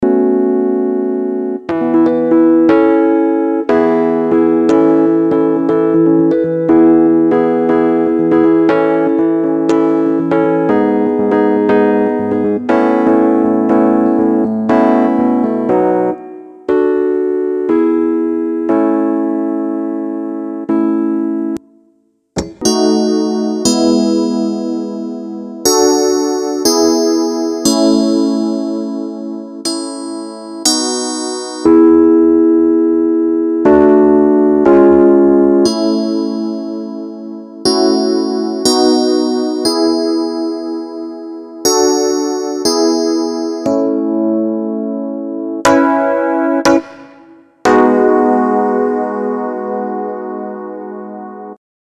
fx-demo pa3x el piano vari.mp3